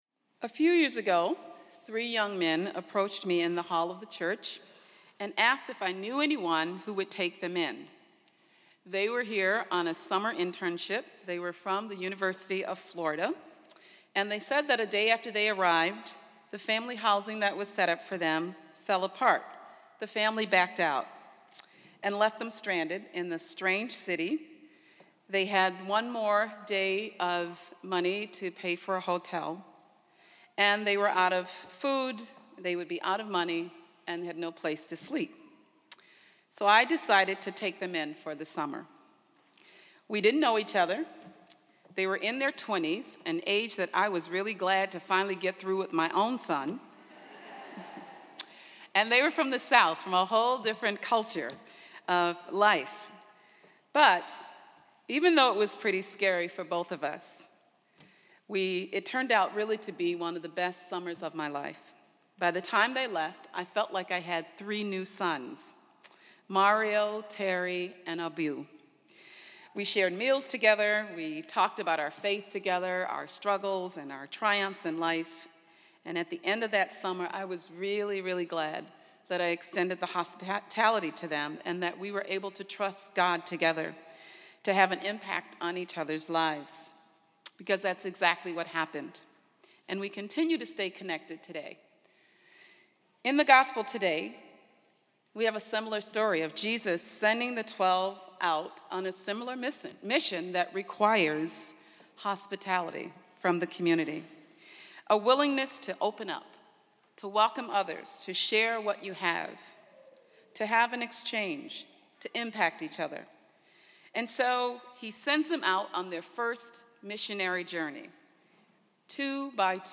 This is a Sunday morning mass at Spiritus Christi Church in Rochester, NY.